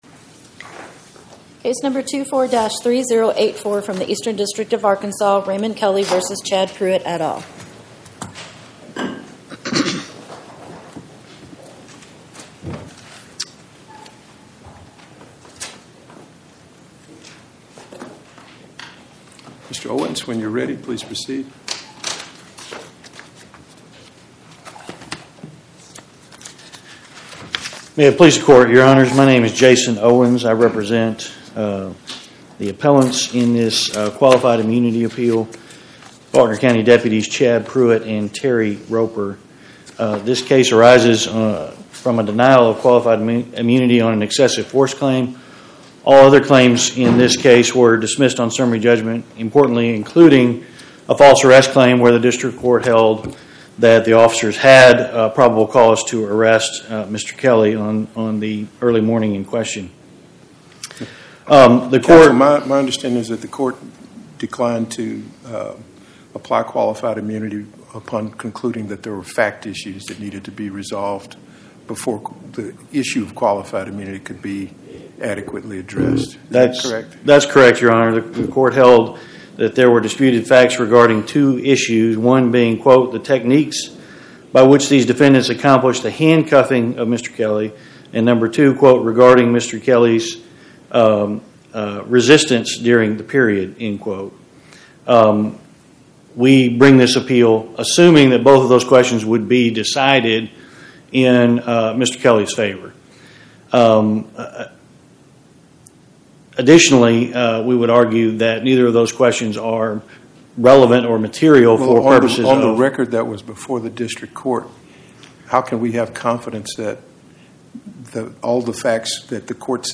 Oral argument